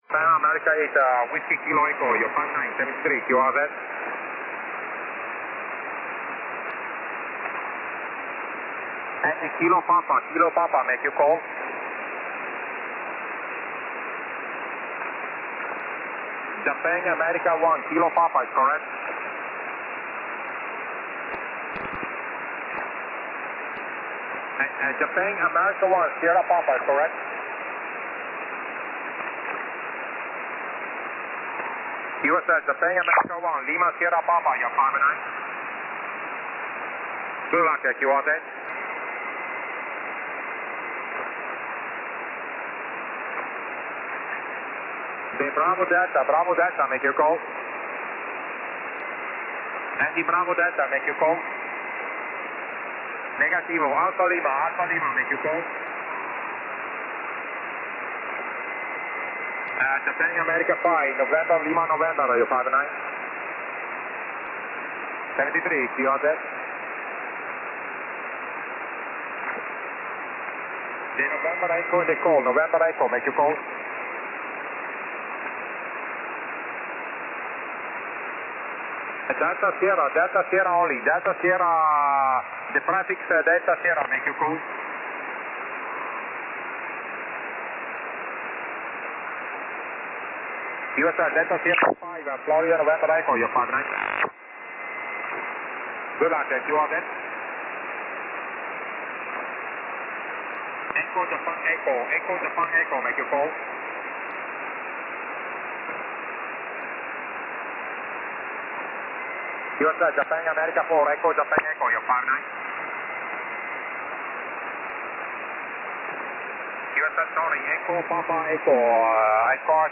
2013/Sep/05 0842z 3B9EME 24.950MHz SSB